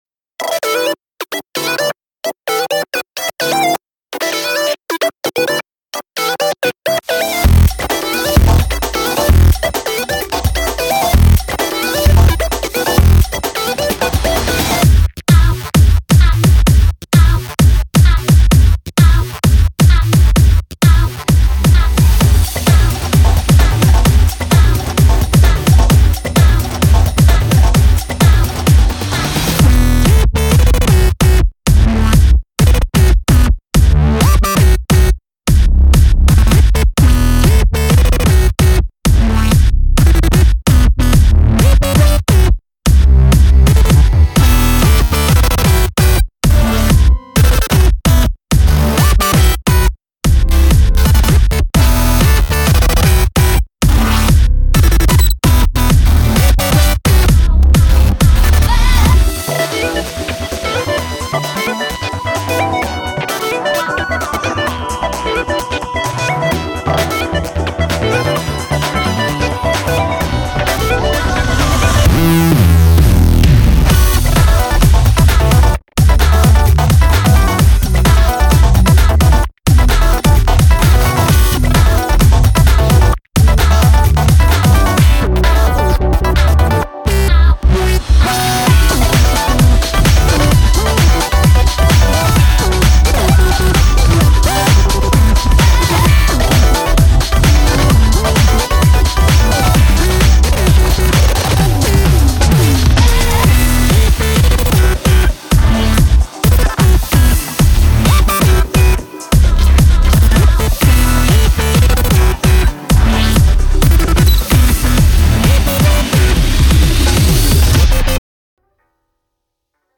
BPM130
Audio QualityPerfect (High Quality)
Blame the weird ass rhythms in this song.